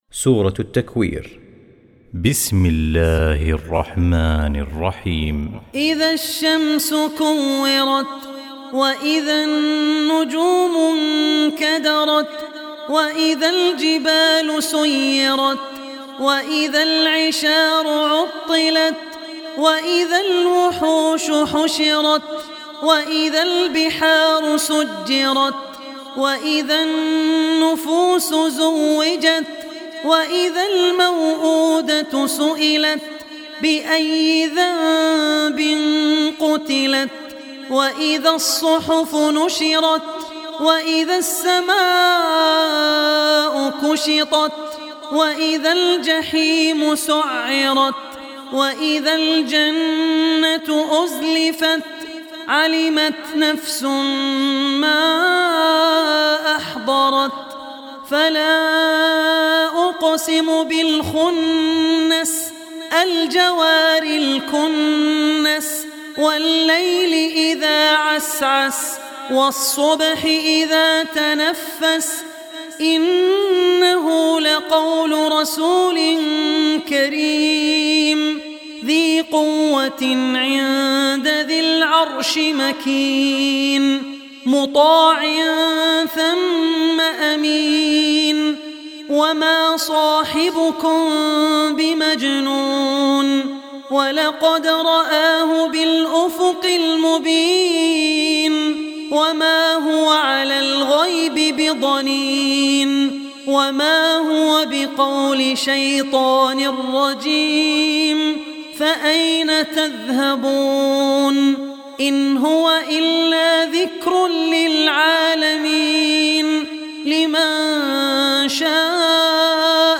Surah Takwir Recitation by Abdur Rehman Al Ossi